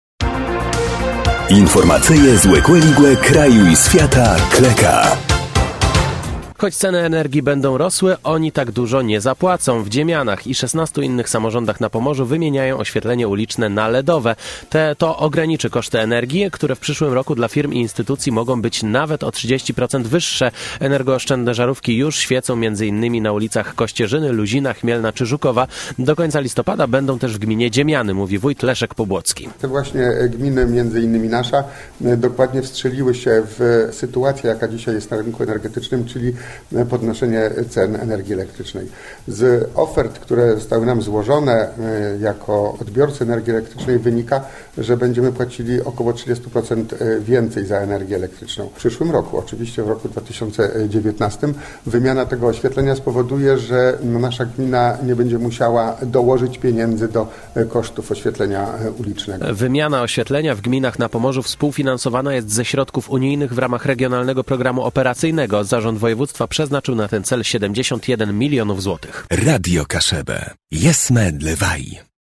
– Do końca listopada będą też w gminie Dziemiany – mówi wójt Leszek Pobłocki.